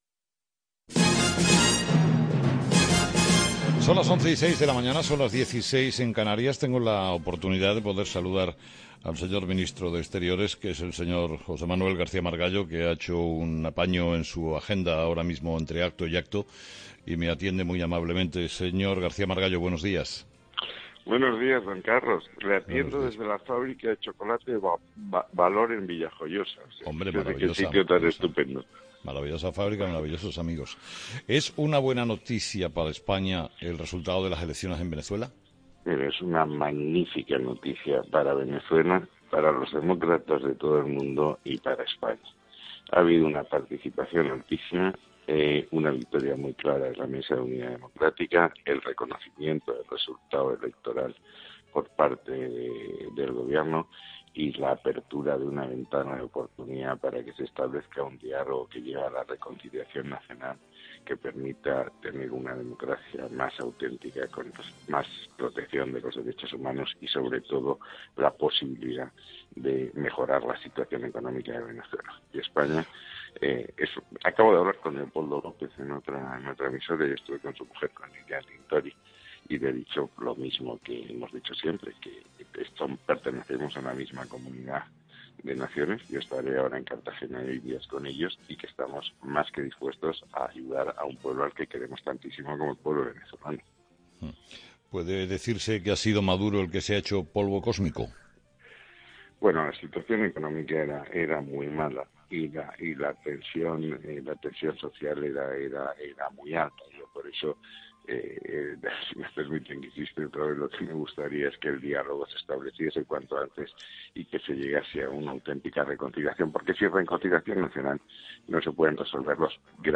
Entrevista a J M.G. Margallo COPE
Entrevistado: "José Manuel García Margallo"